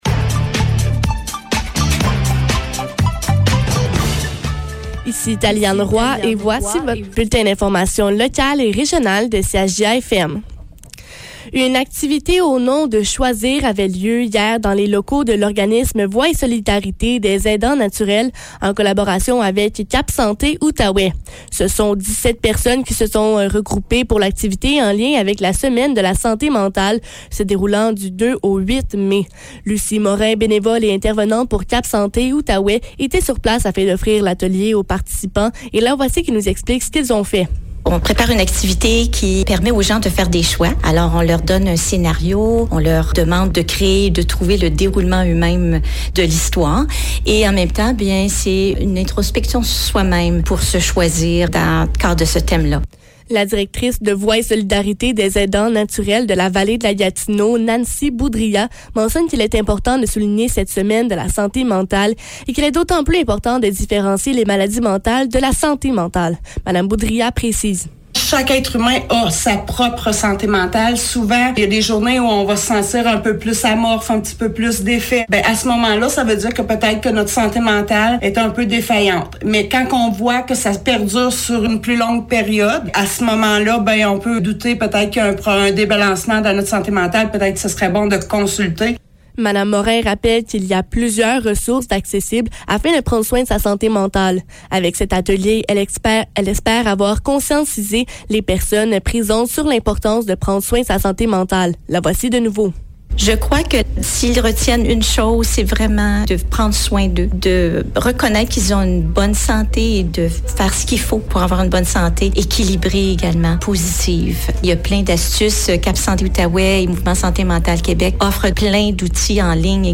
Nouvelles locales - 12 mai 2022 - 12 h